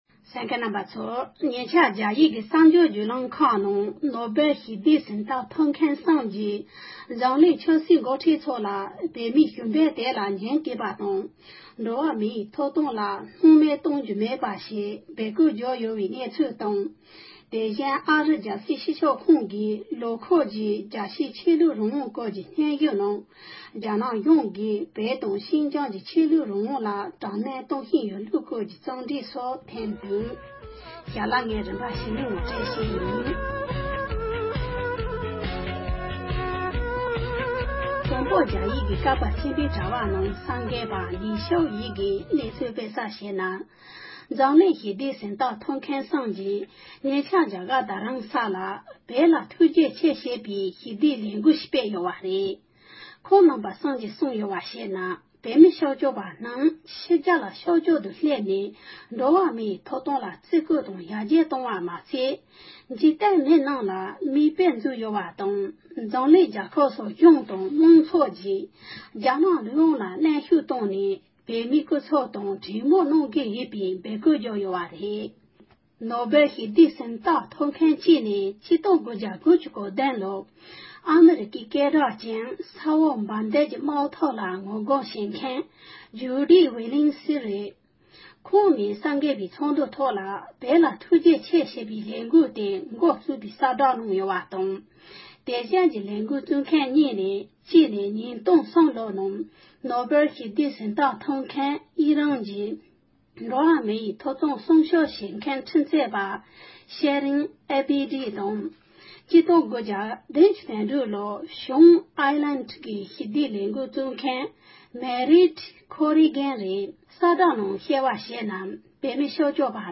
ཕབ་བསྒྱུར་དང་སྙན་སྒྲོན་ཞུས་པ་ཞིག་ལ་གསན་རོགས༎